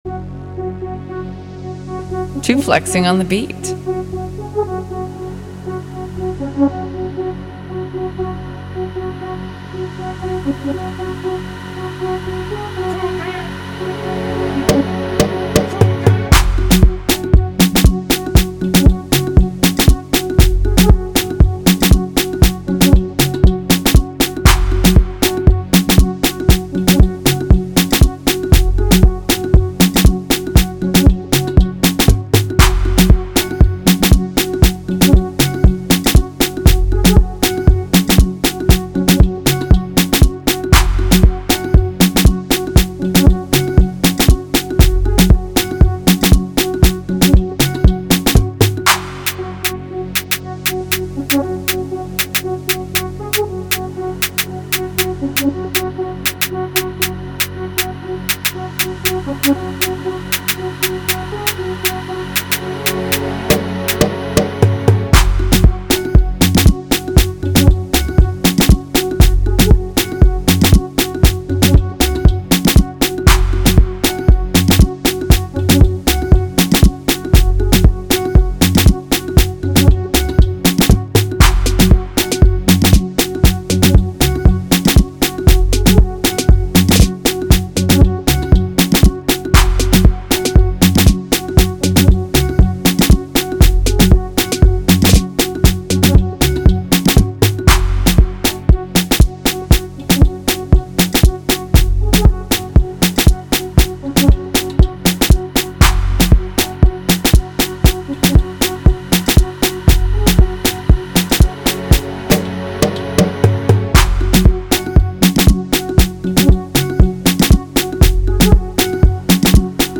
Free beat